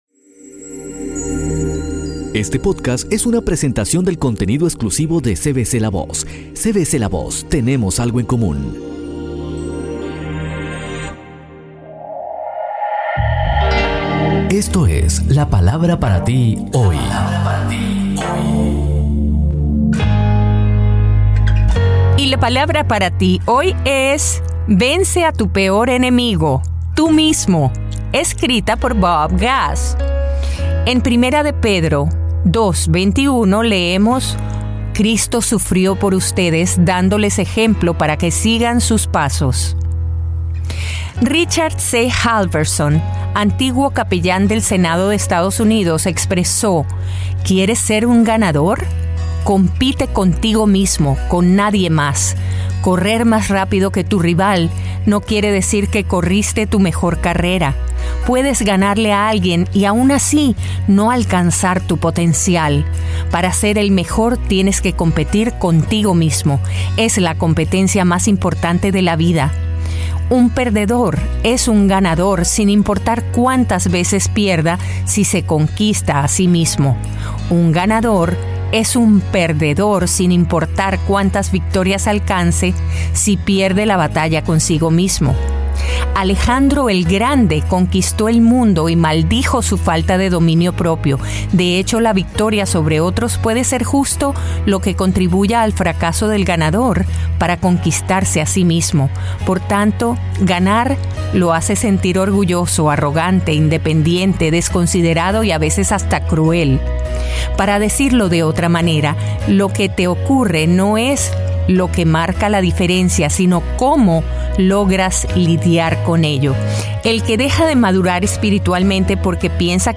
Un nuevo devocional escrito por Bob Gass y narrado por la dulce voz de Elluz Peraza. A veces ese enemigo que tenemos, no lo vemos claramente o lo vemos más grande de lo que realmente es.